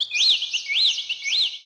birdcall05.wav